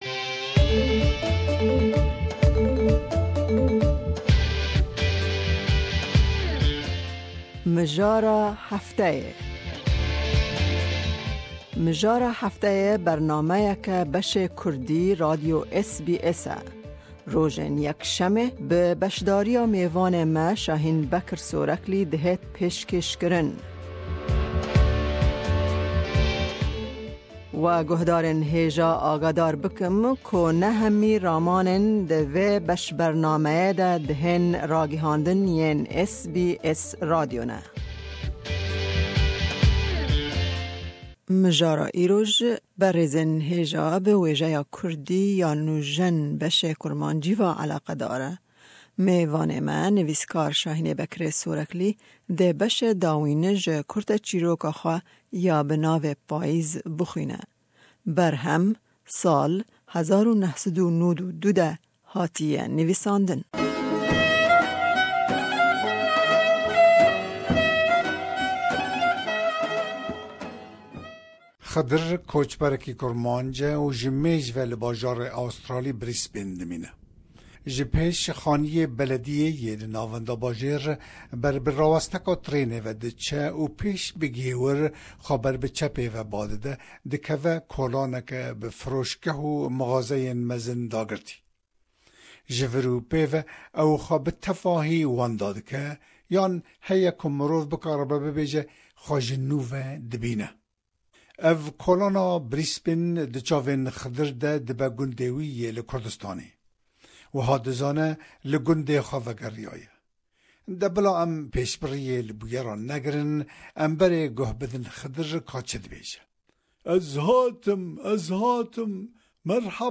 dê beshê dawîn ji kurteçîroka xwe ya bi navê Payîz bixwîne.